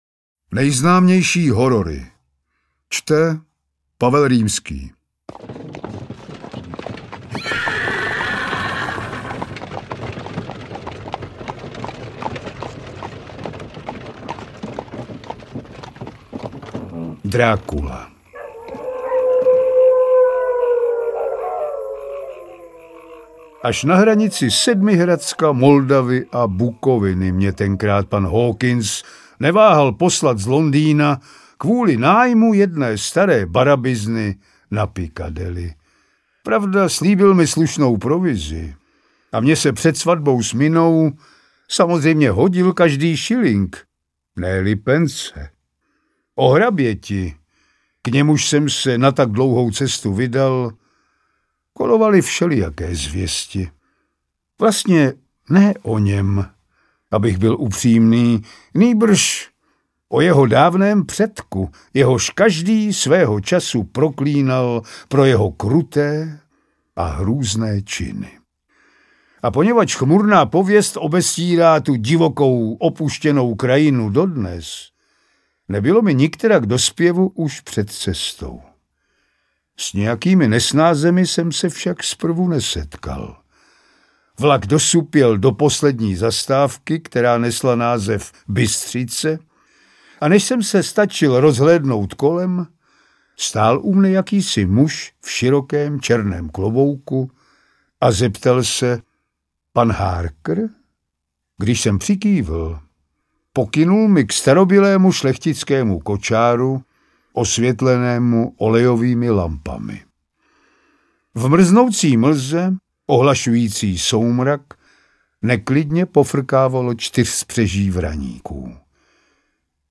Interpret:  Pavel Rímský
AudioKniha ke stažení, 11 x mp3, délka 4 hod. 15 min., velikost 233,3 MB, česky